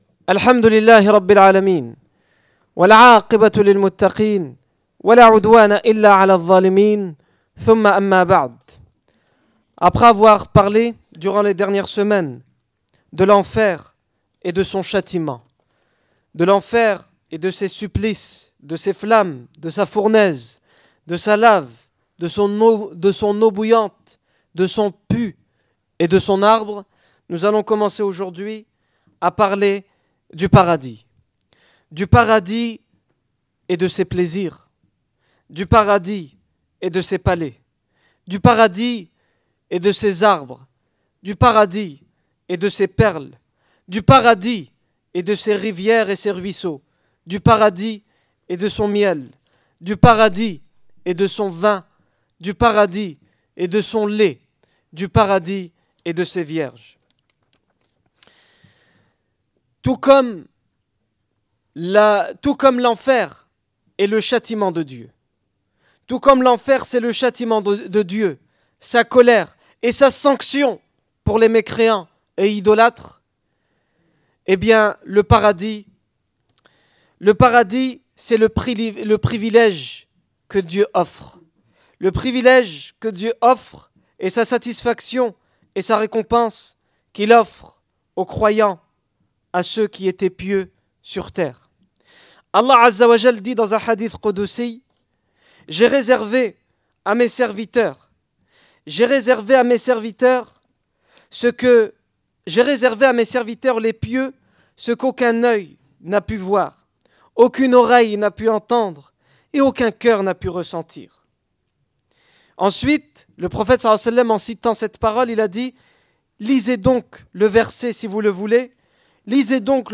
Discours du 26 mars 2010
Discours du vendredi